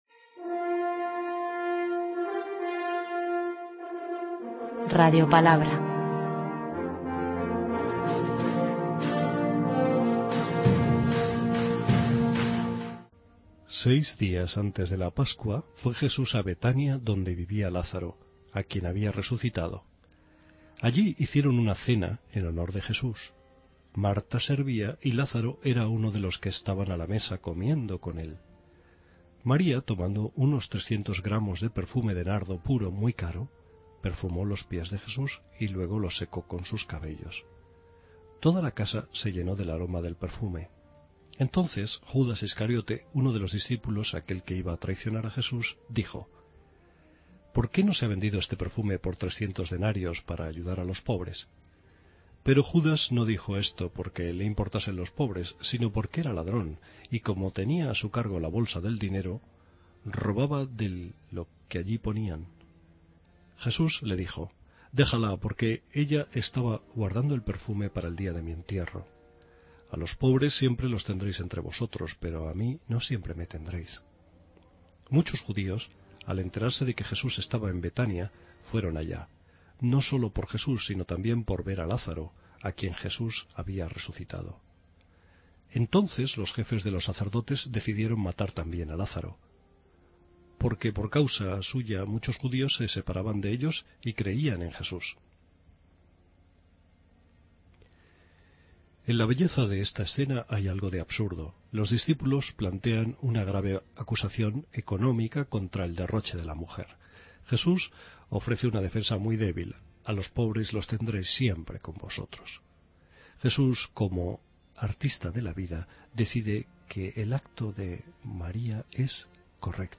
Lectura del santo evangelio de hoy según San Juan 12,1-11